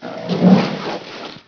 c_rhino_atk1.wav